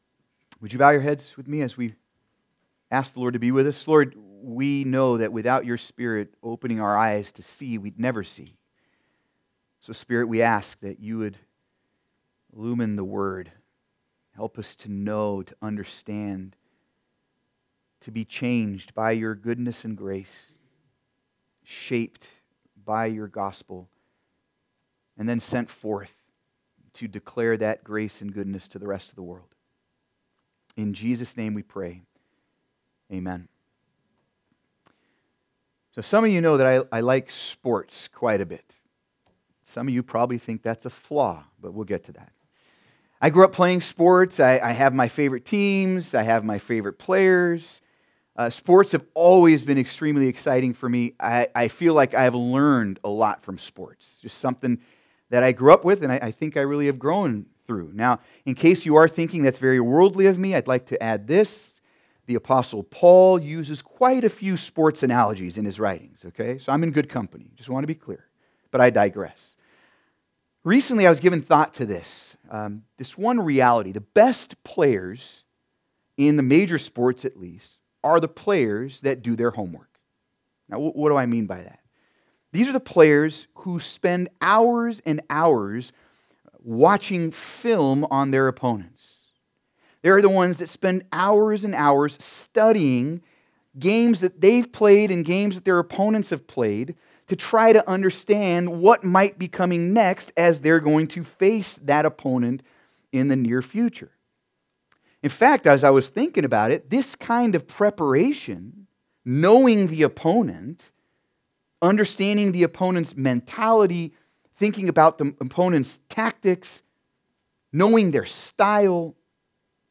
Sermon
Service Type: Sunday Service